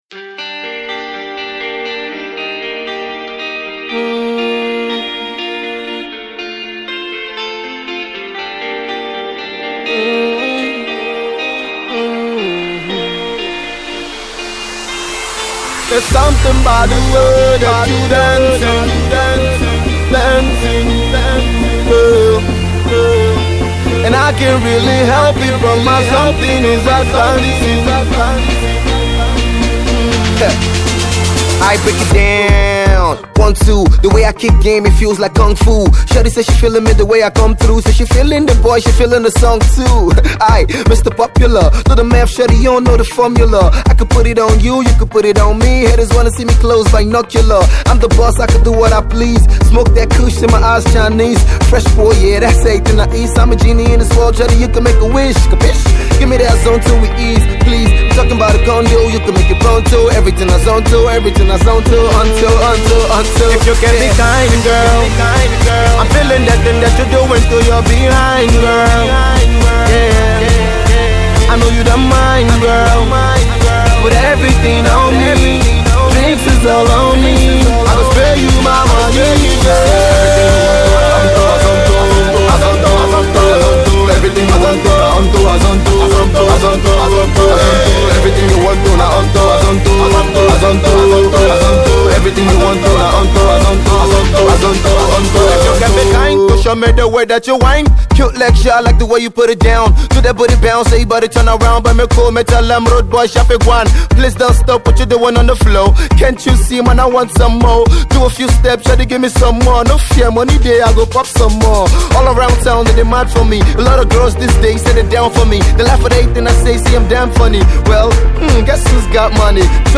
is the young rapper who
quite the club -banging tune